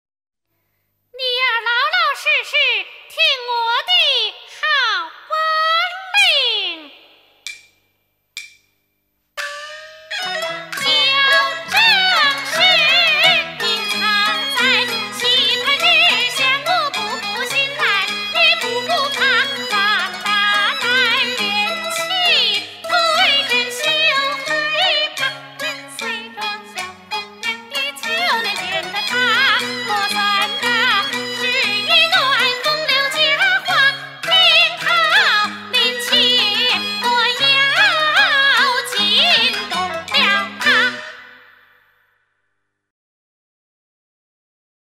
京剧